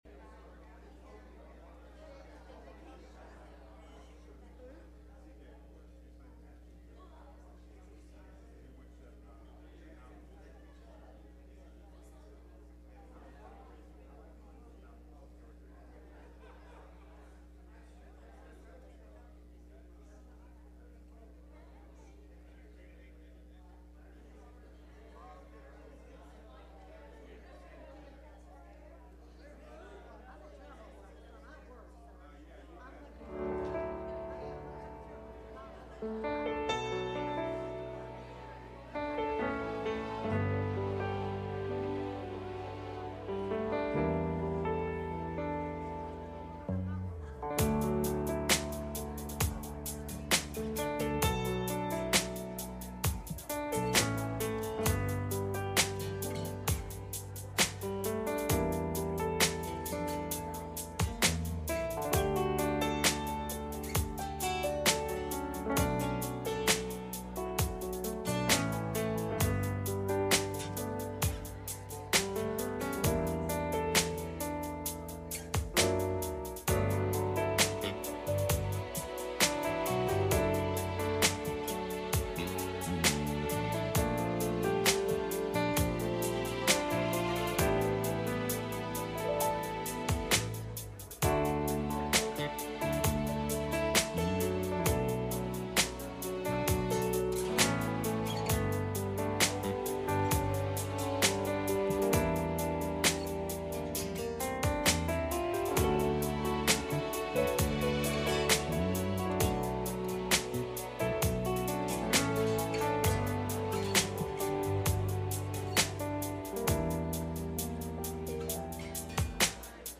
Message Passage: Genesis 12:1-12 Service Type: Sunday Morning « Prepared For Anything